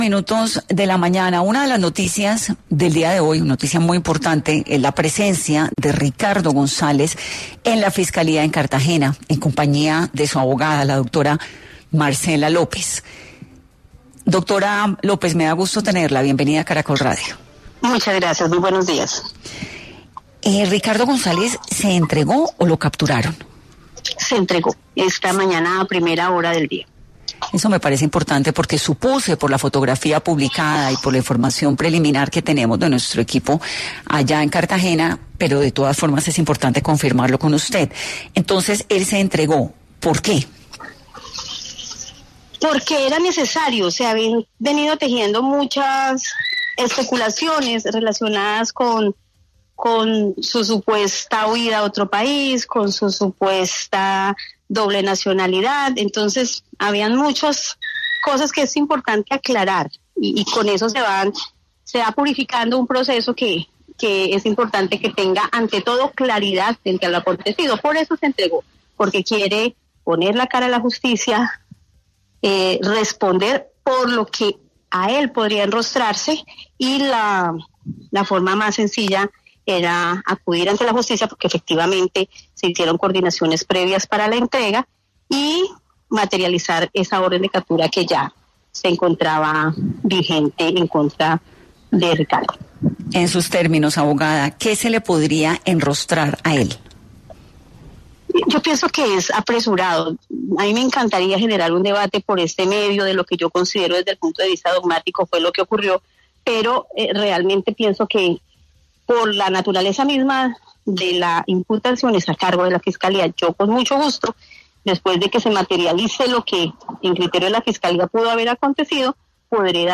En una entrevista con Caracol Radio